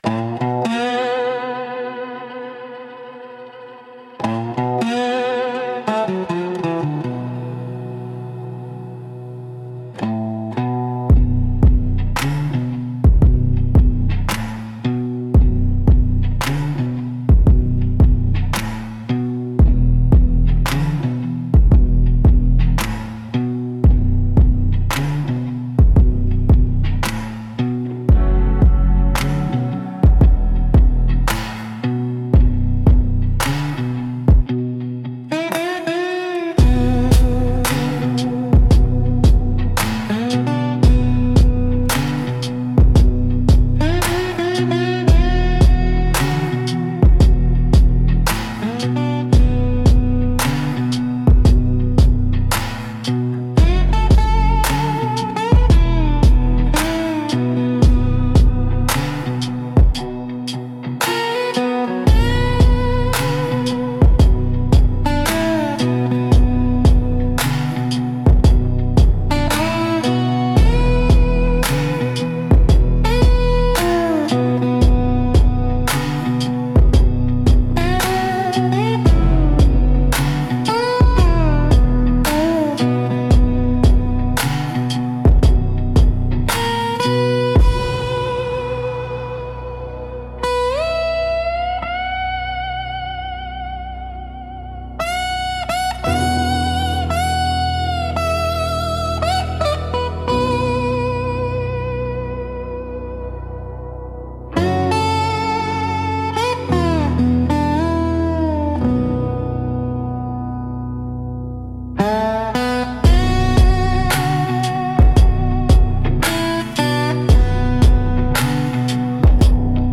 Instrumental - 2.48 The Raven’s Pulse